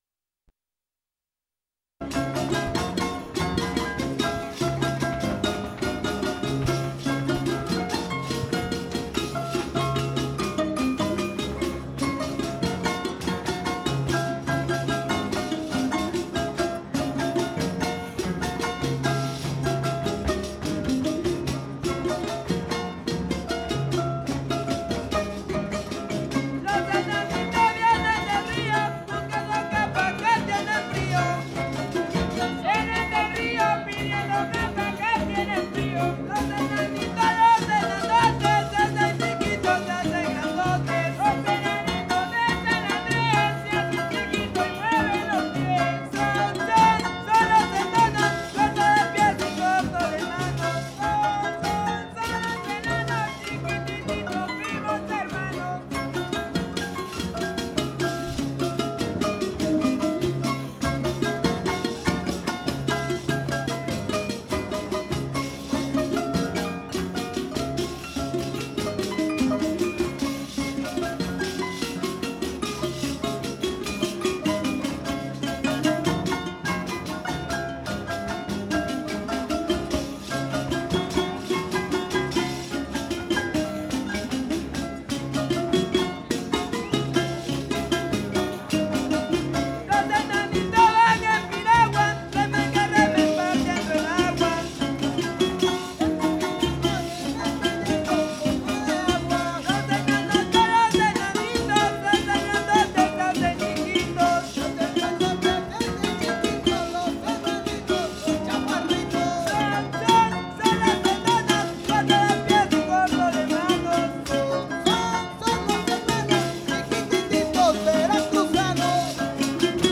Sexto Encuentro de Etnomusicología. Fandango de clausura